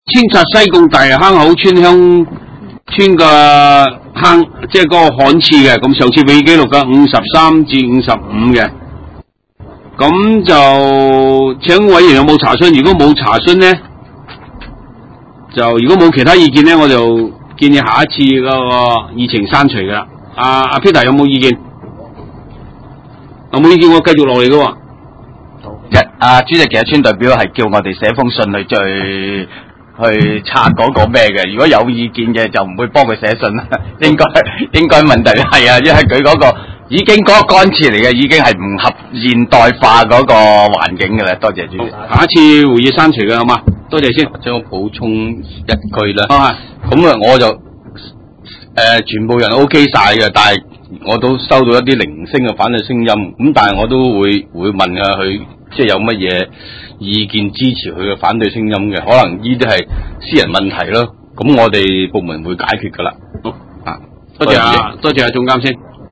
地點：西貢區議會會議室